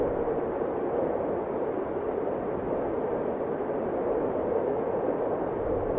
wind.ogg